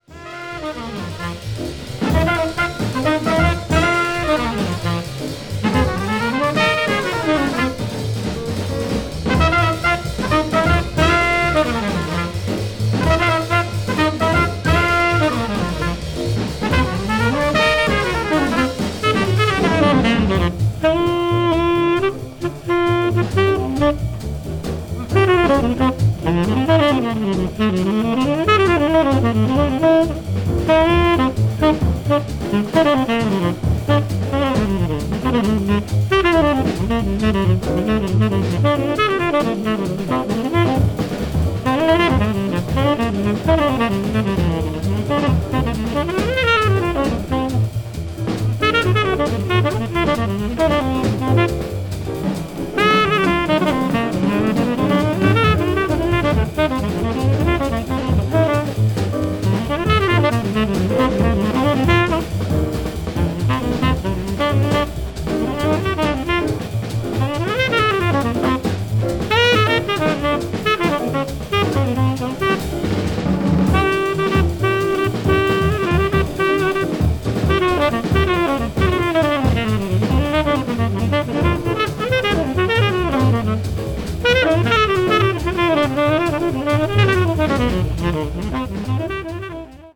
quintet
hard bop   modern jazz